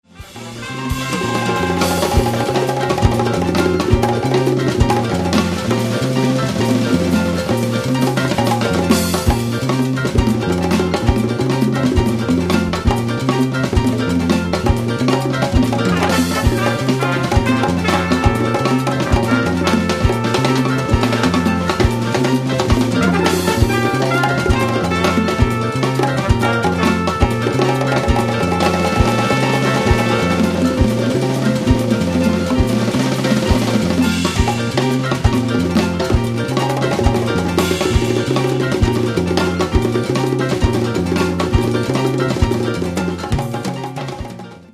Playing congas and a full arsenal of latin instruments